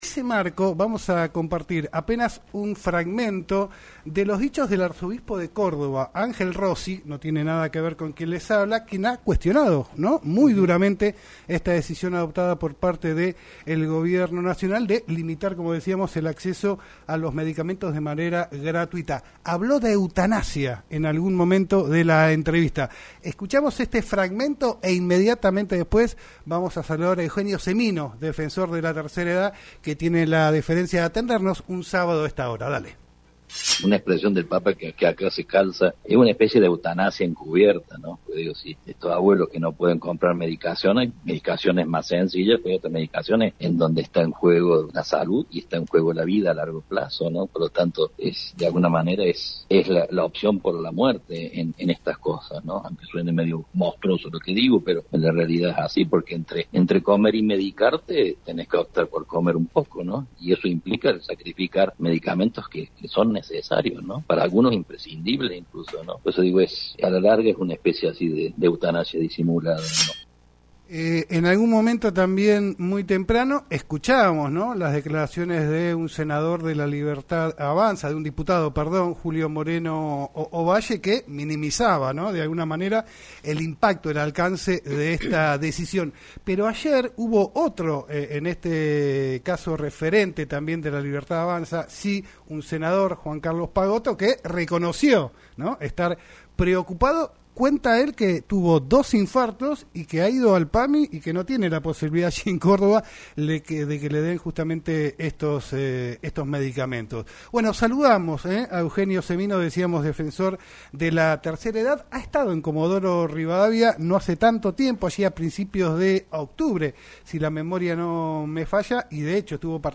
Eugenio Semino, Defensor de la Tercera Edad, habló en “El Quirófano” por LaCienPuntoUno sobre la decisión del gobierno nacional de recortar el subsidio de los medicamentos a los jubilados que ganan más de $390.000, tienen prepaga o un auto de menos de 10 años.